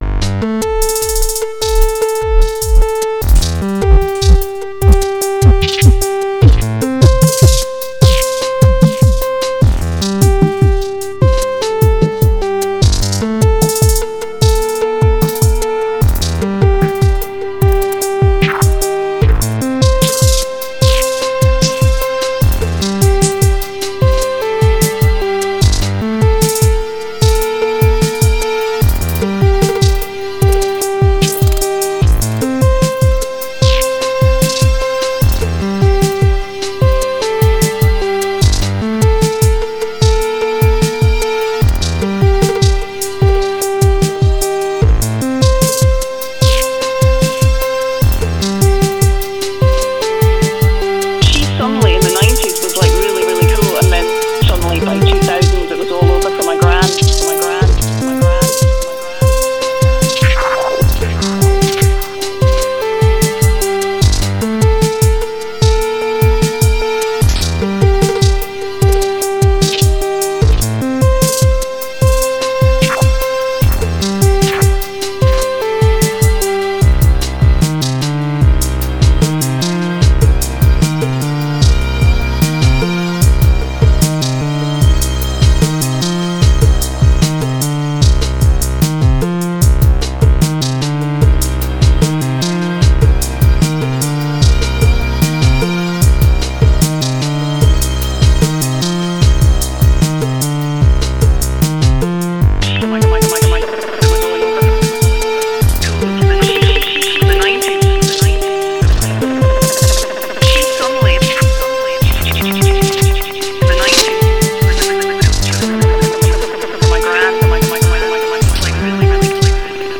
I sampled randomly from the radio and got a slightly morbid snippet of a lady talking about her gran who’s passed away.
Still finding it a little tough to use more than 5 tracks and not have things sound muddled but that’s on me.
Using it probably explains some of that muddiness I mention.